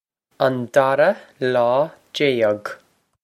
an dara lá déag on dorrah law day-ug
This is an approximate phonetic pronunciation of the phrase.